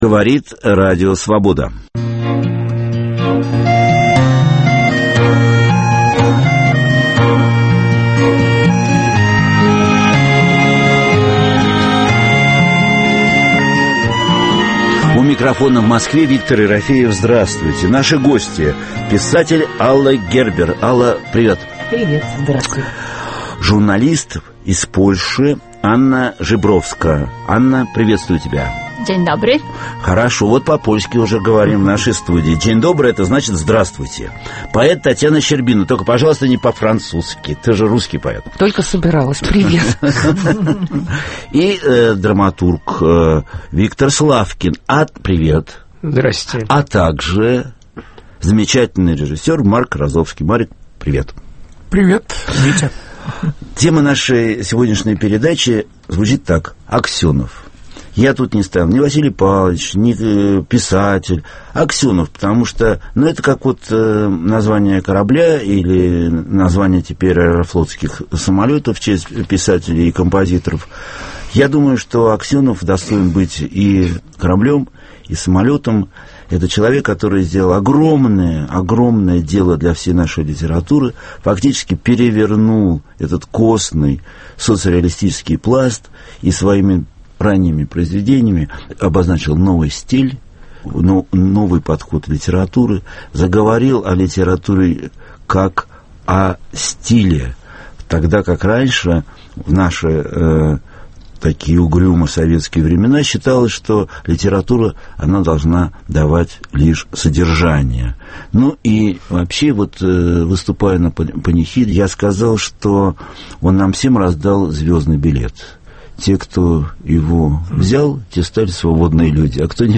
Живой опыт самопознания в прямом эфире с участием слушателей, который ведет писатель Виктор Ерофеев. Это попытка определить наши главные ценности, понять, кто мы такие, о чем мы спорим, как ищем и находим самих себя.